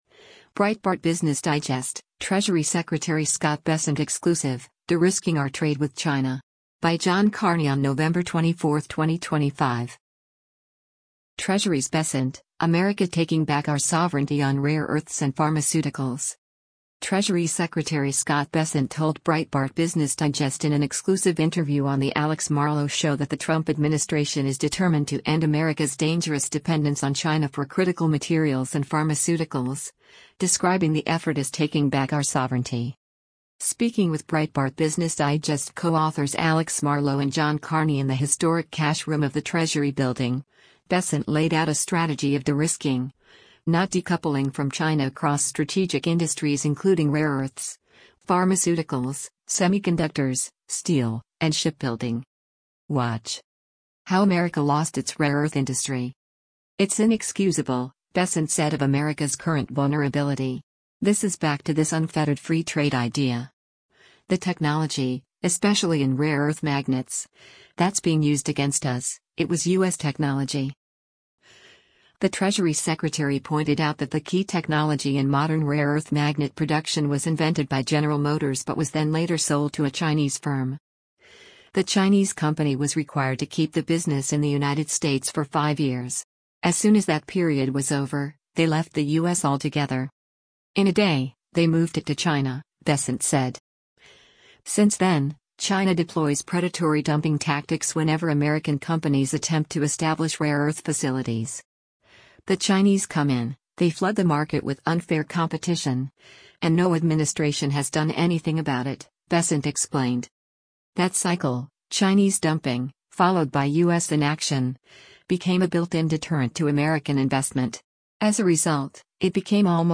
U.S. Treasury Secretary Scott Bessent sits for an interview with Breitbart's Alex Mar